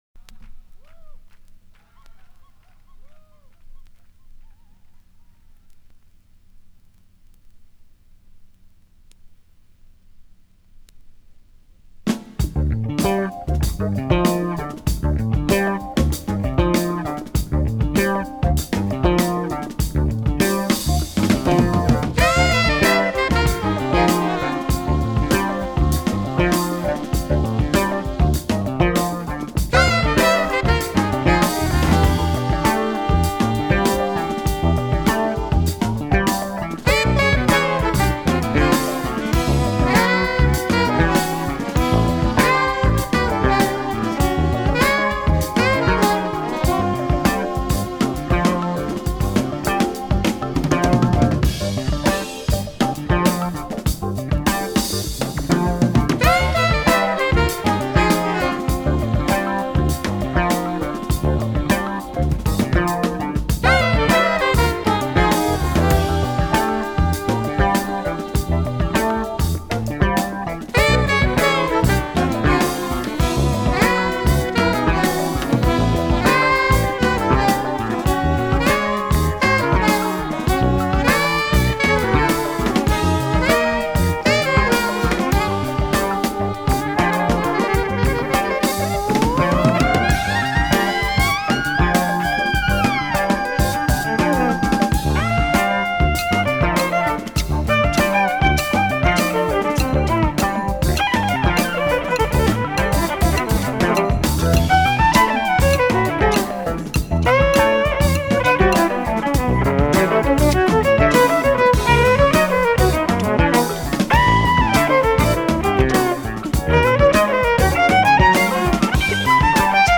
Это как раз по 1:03 слышно - в тв версии рояль во всей красе, а в этой, типа правильной стерео хай фай версии он в опе чёт невнятное играет.
В этом случае я за тёплый плёночный аналог))